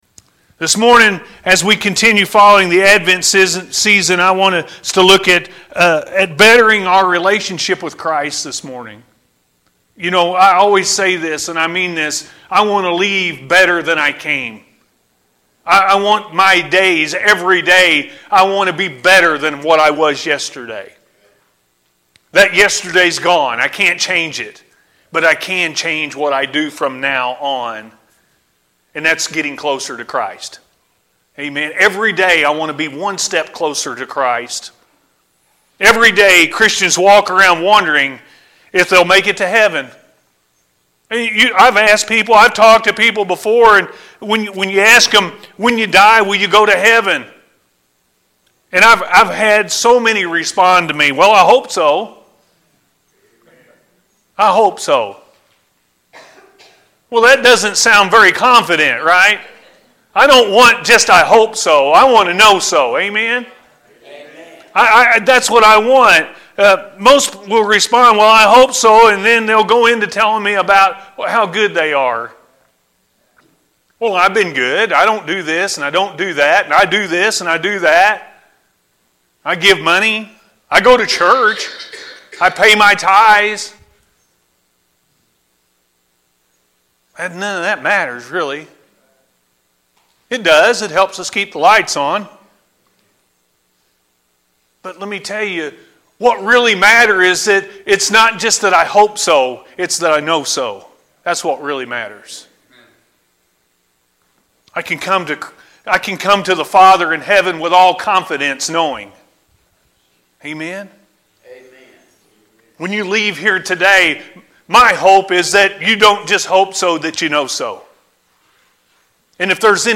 Making Our Relationship With Christ Better- A.M. Service